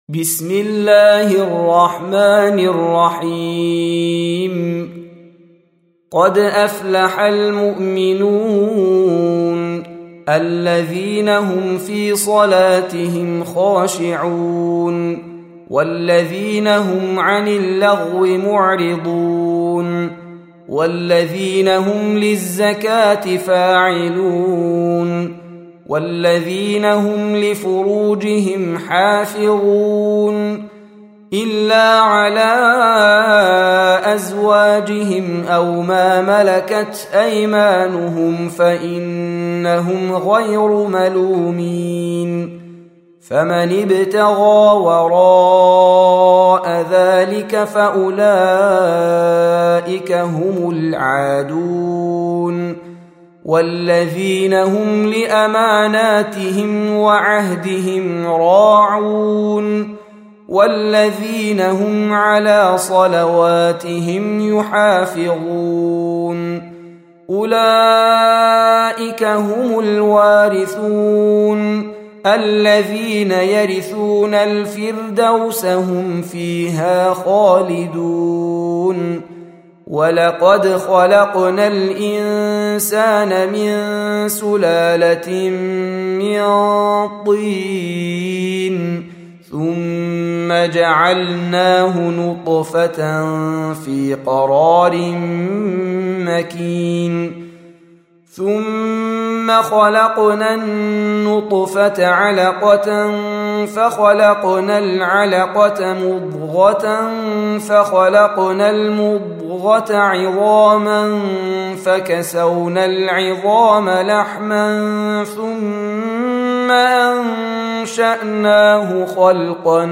Surah Repeating تكرار السورة Download Surah حمّل السورة Reciting Murattalah Audio for 23. Surah Al-Mu'min�n سورة المؤمنون N.B *Surah Includes Al-Basmalah Reciters Sequents تتابع التلاوات Reciters Repeats تكرار التلاوات